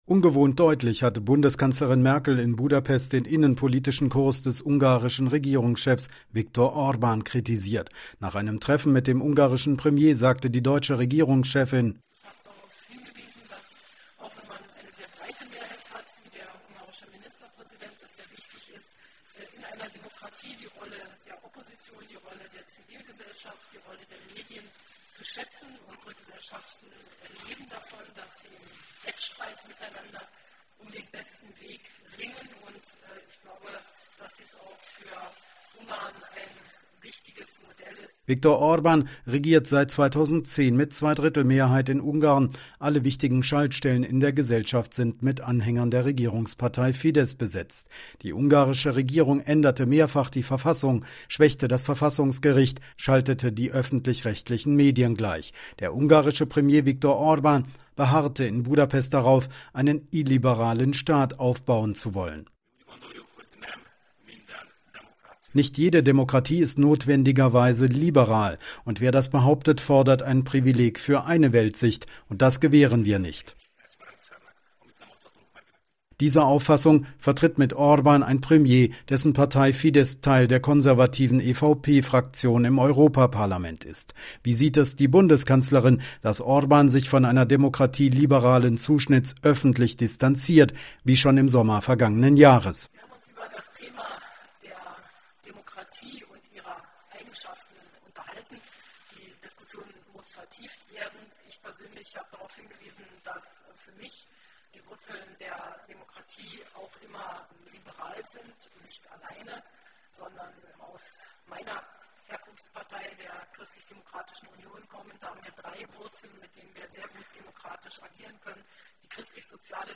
Aus Budapest berichtet